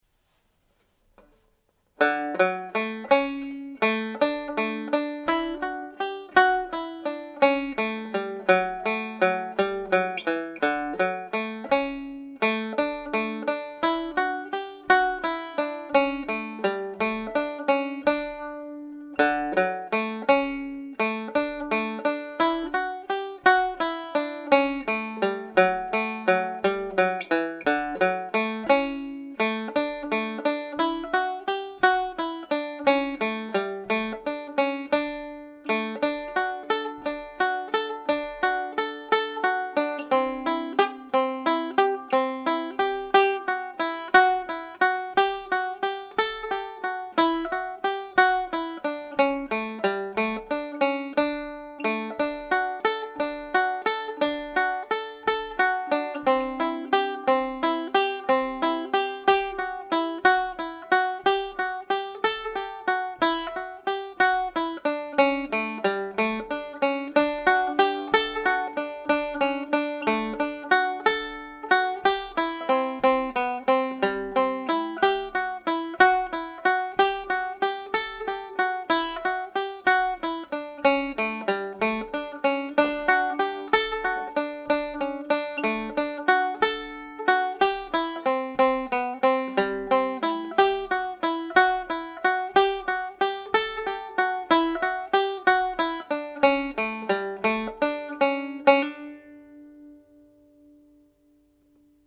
Pure Banjo
Kitty’s Rambles played slowly